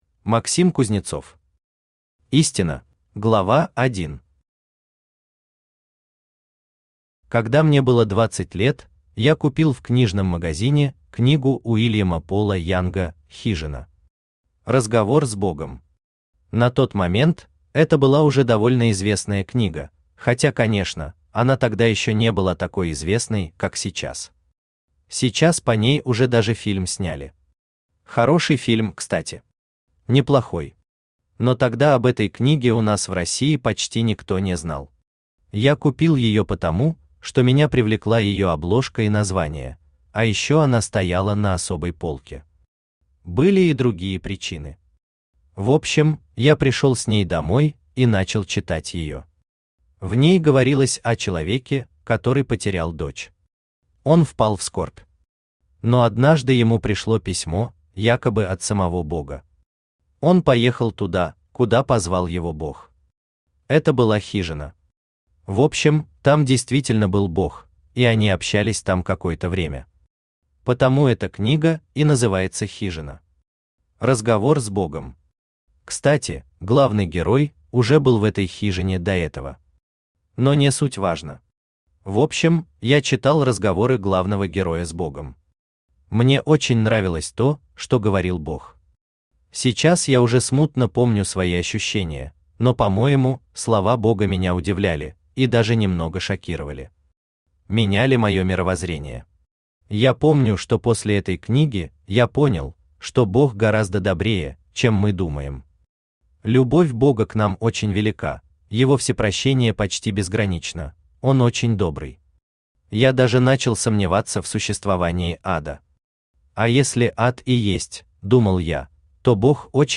Аудиокнига Истина | Библиотека аудиокниг
Aудиокнига Истина Автор Максим Кузнецов Читает аудиокнигу Авточтец ЛитРес.